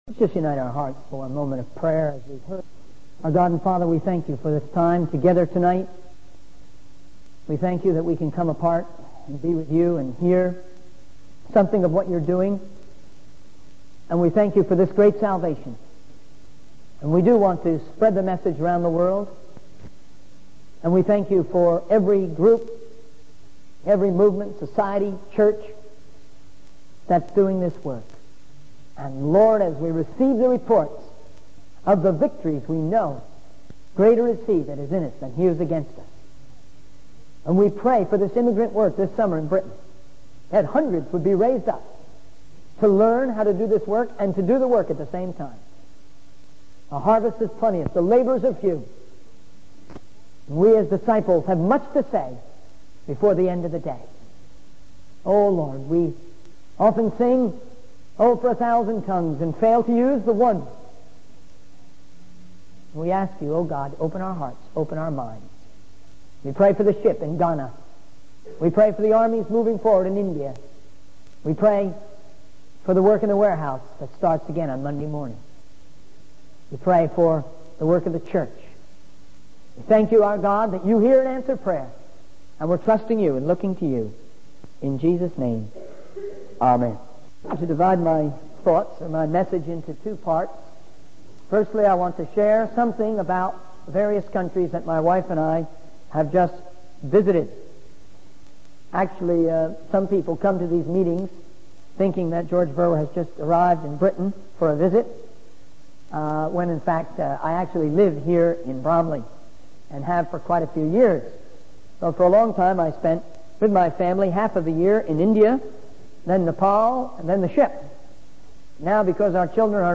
In this sermon, the speaker emphasizes the importance of not only distributing literature but also following up with people to ensure their spiritual growth.